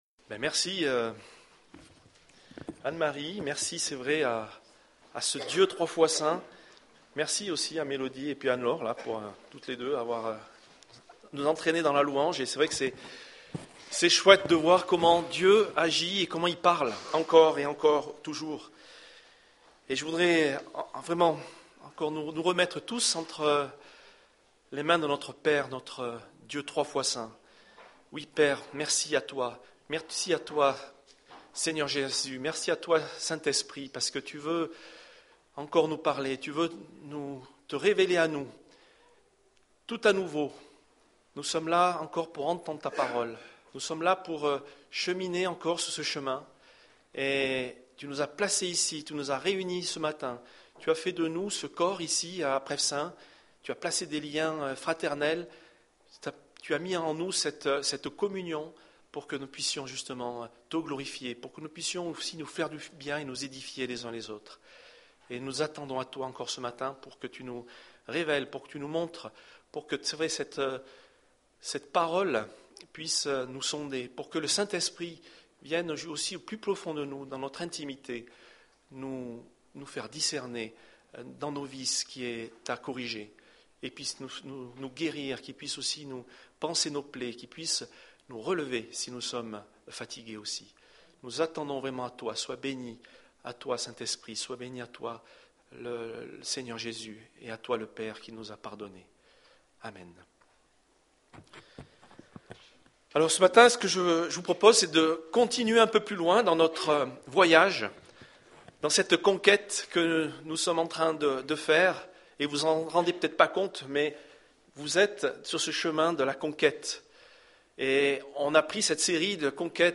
Culte du 15 février 2015